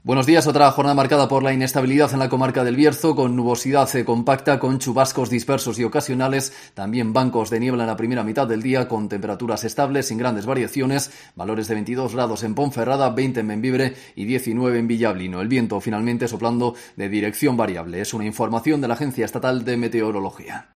Previsión del tiempo Bierzo